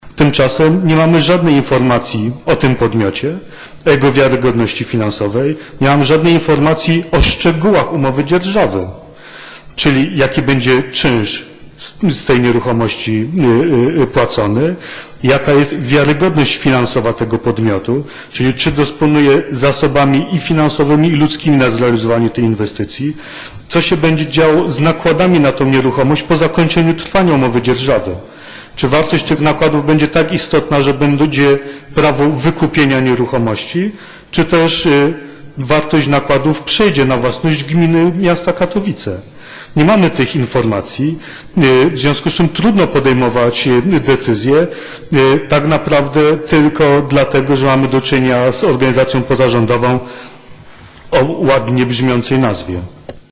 Decydujemy o majątku gminy, który powinien pracować jak najbardziej efektywnie w wymiarze i finansowym i społecznym – mówił na sesji Arkadiusz Godlewski. – Nie mamy żadnej informacji o tym podmiocie, o jego wiarygodności finansowej, nie mamy żadnej informacji o szczegółach umowy dzierżawy – dodał Godlewski.